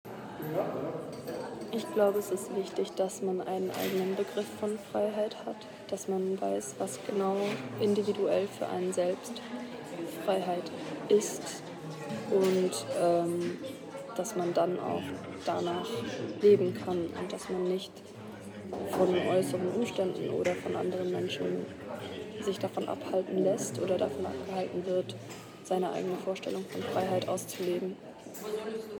Theaterversammlung im Staatstheater Cottbus @ Cottbus